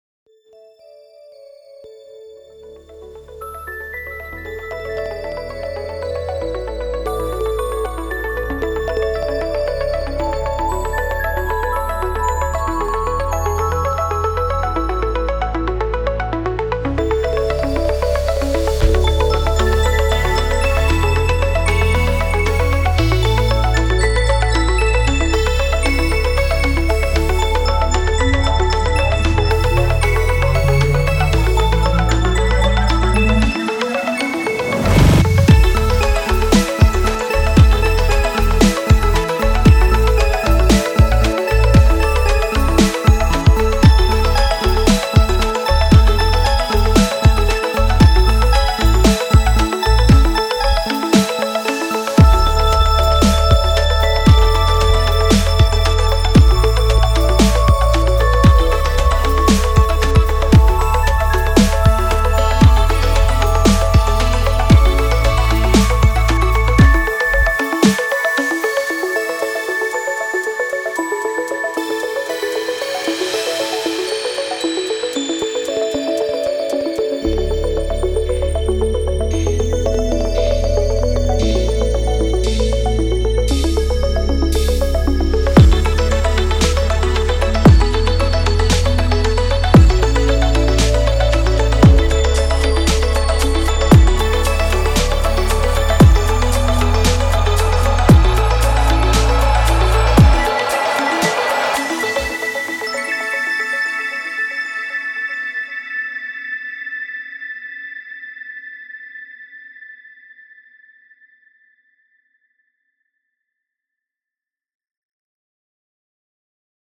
Introducing Crystallion — a mesmerizing collection of 100 cinematic bell, mallet, crystal, and chime presets for Reveal Sound's Spire.
Designed to infuse your compositions with clarity, purity, and shimmering atmosphere.
From delicate bell tones to resonant mallet strikes and ethereal crystalline textures, Crystallion offers a diverse palette of sounds that evoke both wonder and refinement.
• * The video and audio demos contain presets played from Crystallion sound bank, every single sound is created from scratch with Spire.
• * All sounds of video and audio demos are from Crystallion (except drums and additional arrangements).